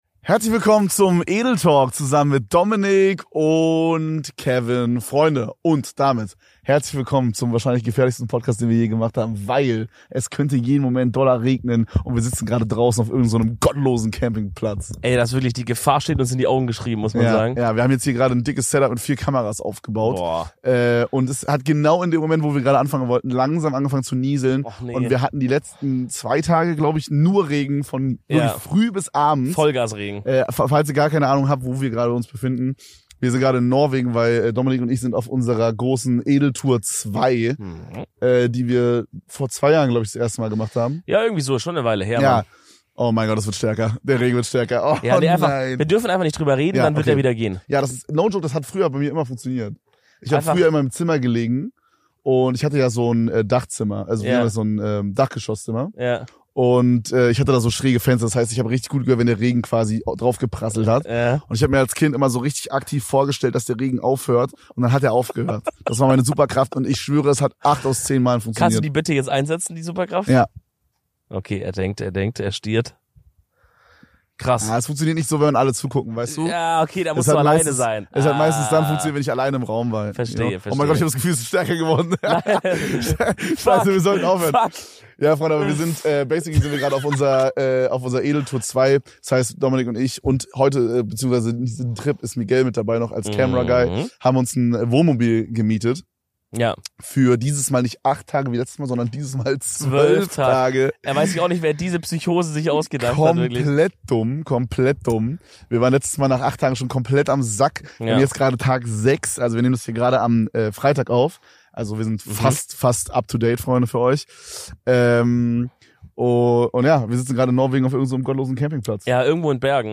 Heute befinden wir uns im schönen Norwegen und nehmen für euch frisch von der Edeltour eine neue Folge auf.